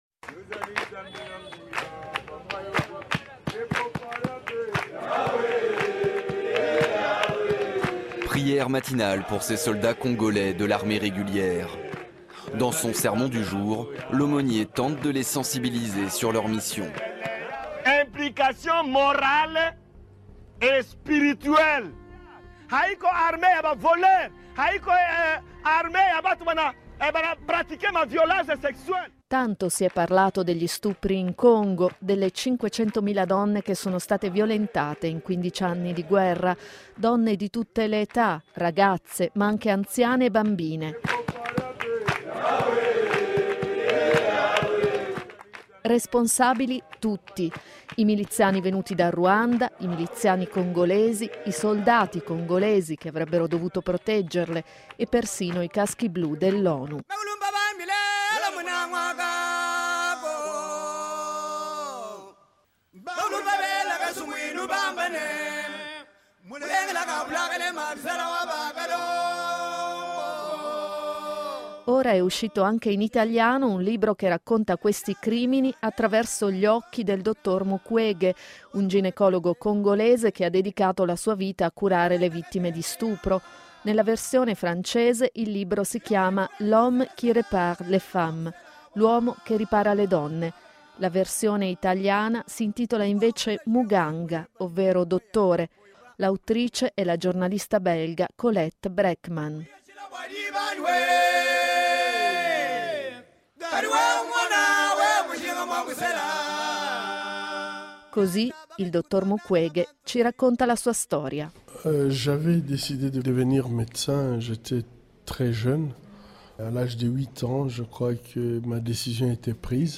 Proprio quest’ultimo è stato ospite di "Laser"e oggi vi riponiamo la sua intervista